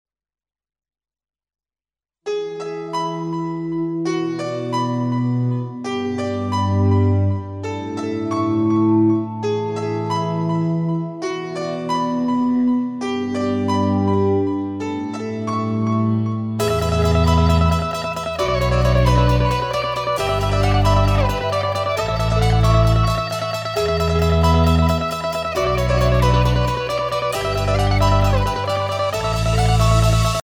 Я брал версию из сингла, она звонче smile.gif